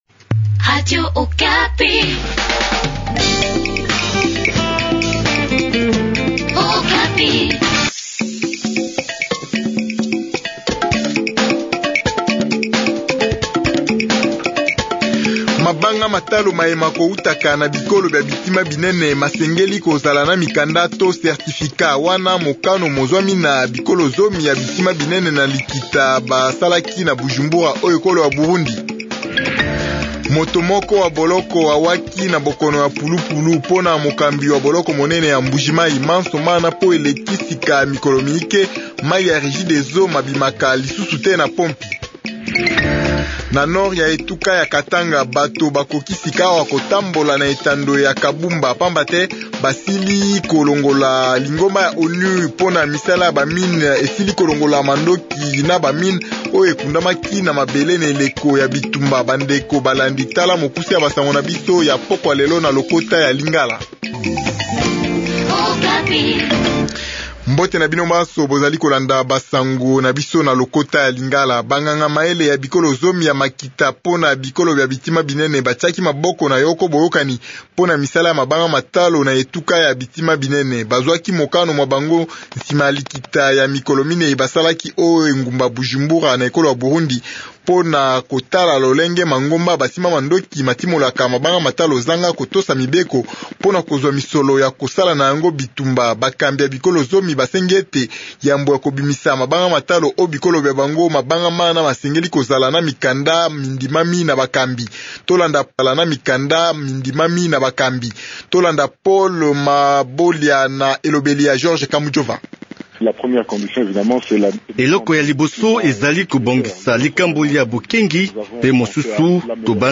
Journal lingala du soir